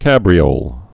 (kăbrē-ōl)